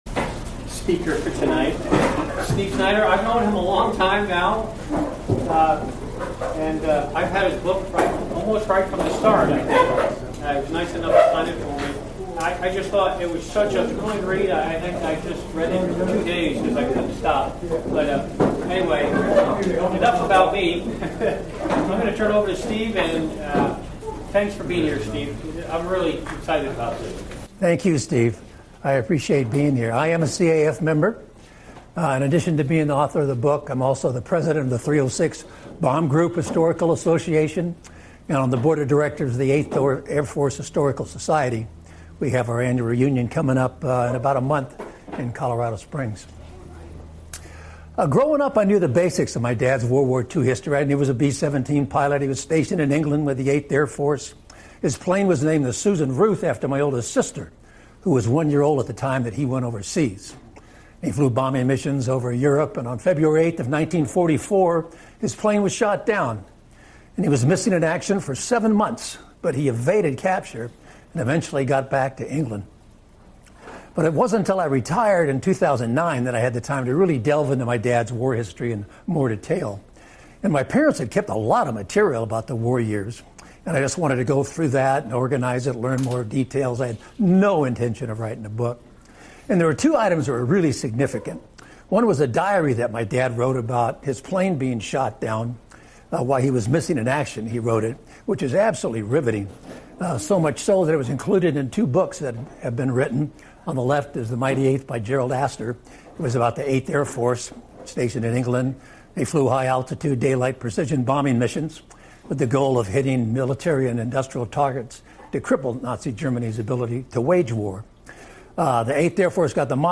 Presentation at the Commemorative Air Force Inland Empire Wing